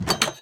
Minecraft Version Minecraft Version latest Latest Release | Latest Snapshot latest / assets / minecraft / sounds / block / iron_door / open1.ogg Compare With Compare With Latest Release | Latest Snapshot